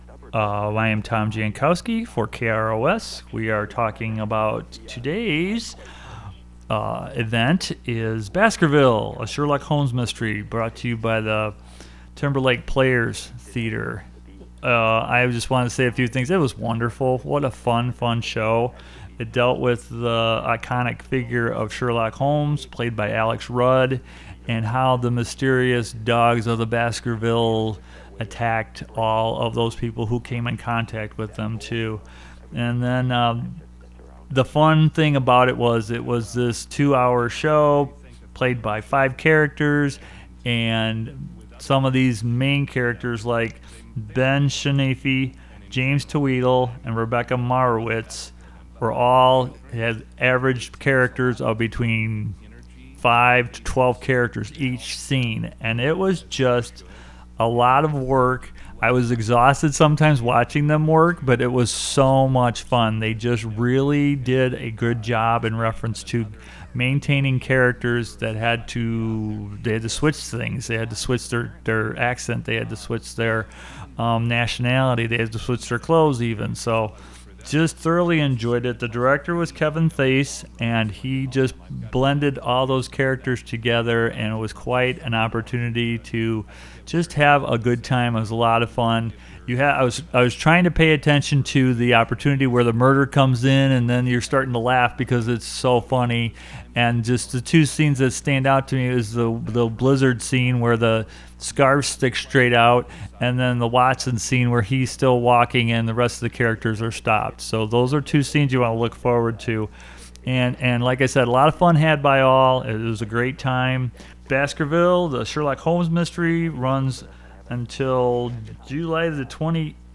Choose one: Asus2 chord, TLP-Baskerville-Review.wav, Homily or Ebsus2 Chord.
TLP-Baskerville-Review.wav